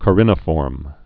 (kə-rĭnə-fôrm)